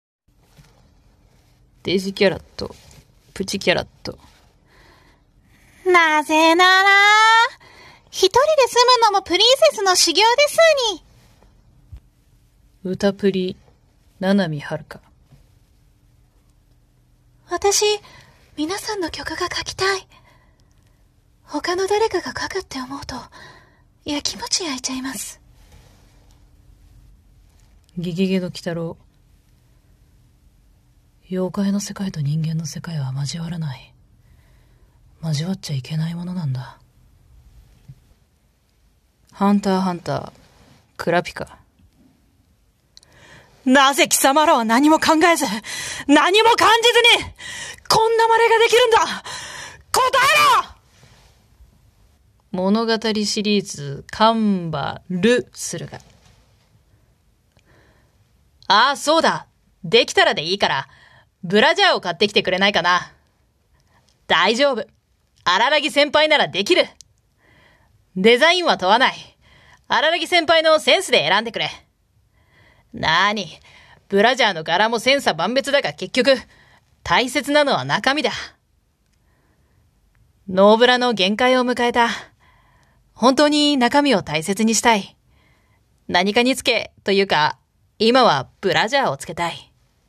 沢城みゆきさんの真似集(超低クオリティ)